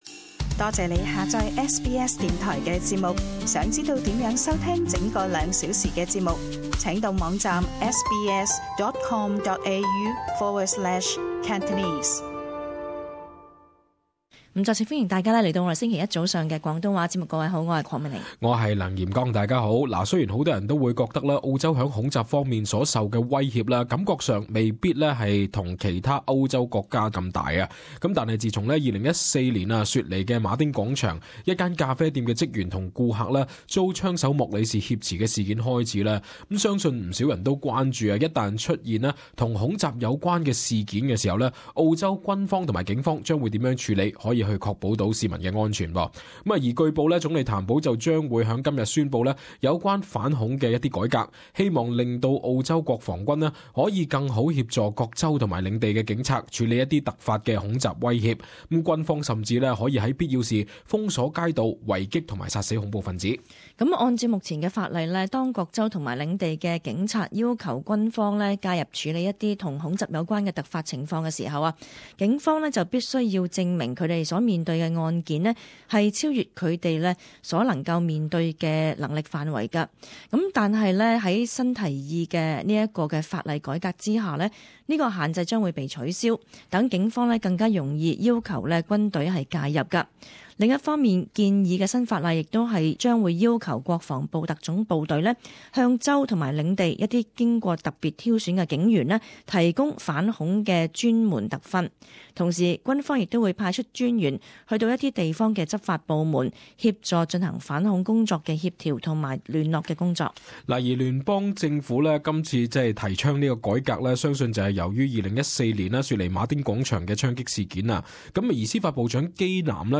【時事報導】譚保政府今日將提反恐法修改